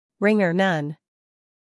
英音/ ˈrɪŋə(r) / 美音/ ˈrɪŋər /